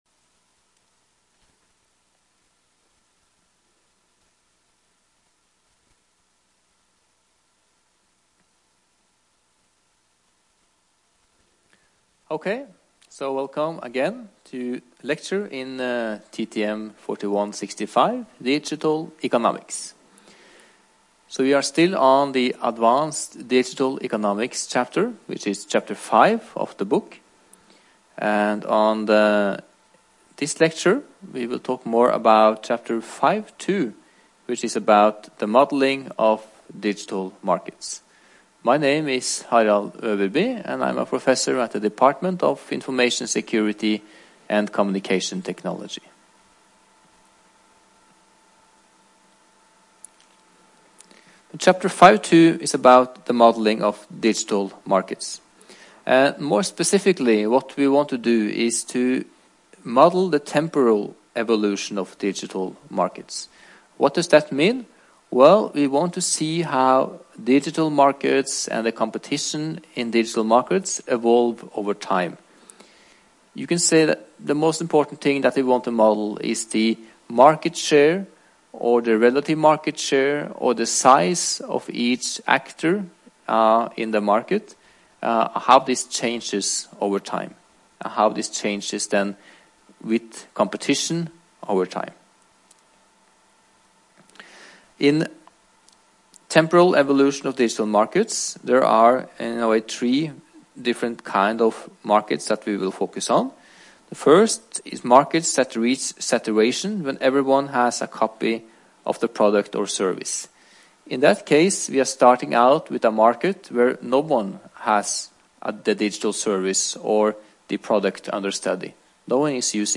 Rom: K102